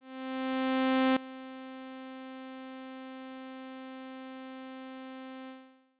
縲仙渕譛ｬ險ｭ螳壹窟=90縲．=X縲ヾ=40縲ヽ=70縲阪
貂幄｡ｰ縺ｪ縺励ｮ髻ｳｼD=0ｼ
Aｼ90縲．ｼ晢ｼ舌↑縺ｮ縺ｧ縲∵怙螟ｧ髻ｳ縺ｸ蜷代°縺｣縺ｦ蠕舌縺ｫ髻ｳ縺悟､ｧ縺阪￥縺ｪ繧翫∵怙螟ｧ髻ｳ縺ｸ蛻ｰ驕皮峩蠕後↓貂幄｡ｰ縺ｪ縺励〒繧ｵ繧ｹ繝繧｣繝ｳ繝ｬ繝吶Ν縺ｫ縺ｪ繧翫∪縺吶る浹縺ｯ蝓ｺ譛ｬ逧縺ｫ貂幄｡ｰ縺吶ｋ繧ゅｮ縺ｪ縺ｮ縺ｧ縲√■繧縺｣縺ｨ驕募柱諢溘′縺ゅｊ縺ｾ縺吶